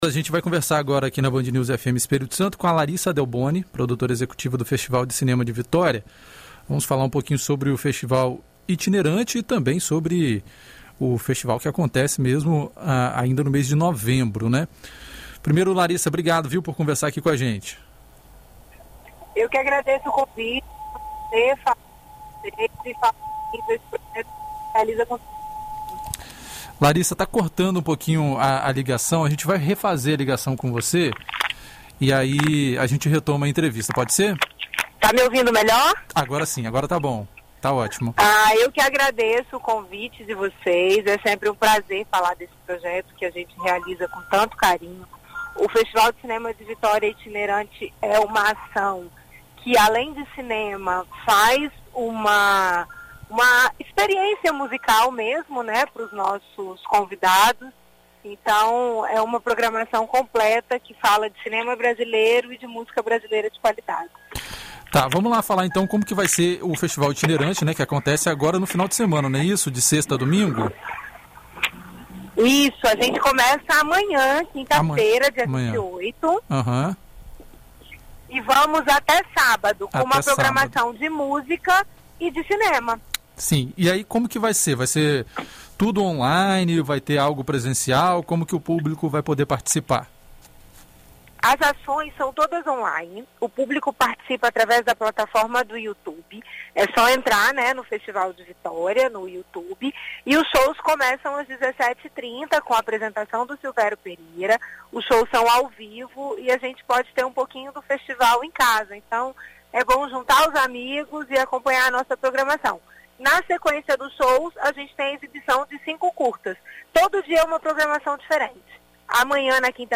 Em entrevista à BandNews FM Espírito Santo nesta quarta-feira